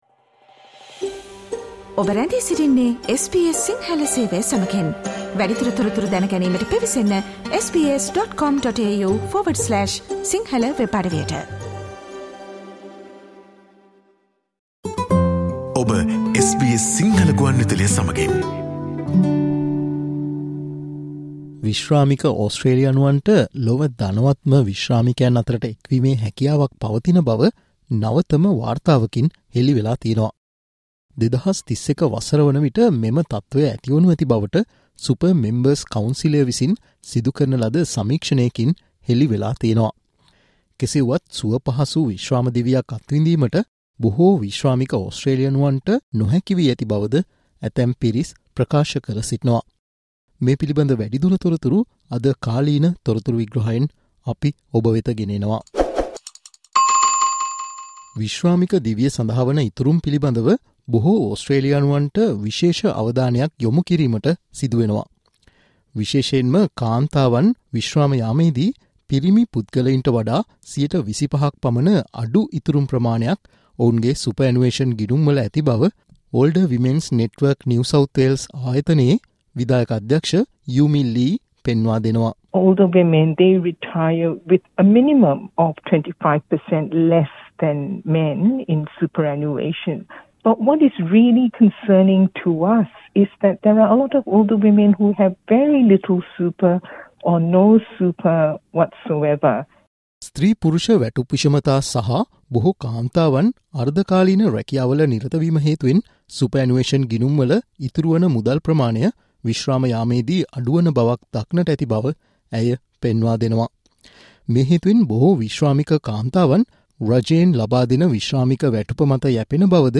SBS Sinhala Newsflash 27 Feb: Efforts continue to find solutions to the disruption of the parent visas
Listen to today's SBS Sinhala Newsflash